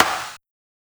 Snares
SNARE_GRITS_N_BACON.wav